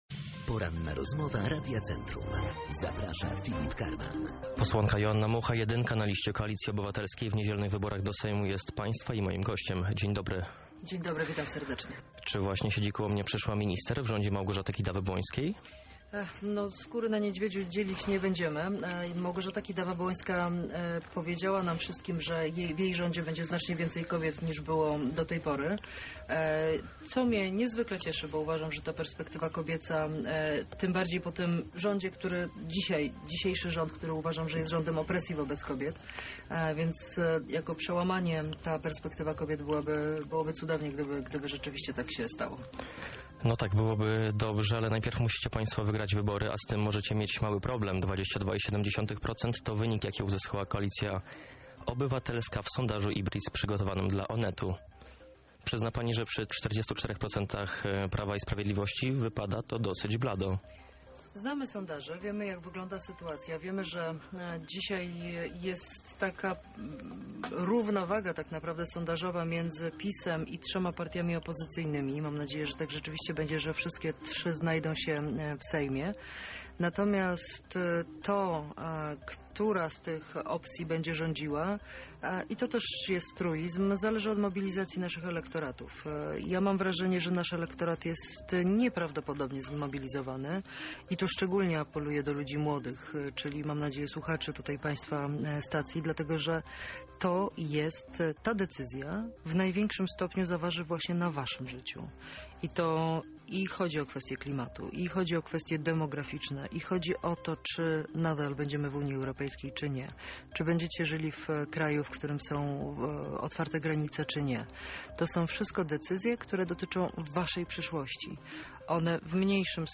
Posłanka Platformy Obywatelskiej, będąca jednocześnie „jedynką” listy Koalicji Obywatelskiej w naszym regionie była gościem Porannej Rozmowy Radia Centrum.
Poranna Rozmowa RC – Joanna Mucha cz. 1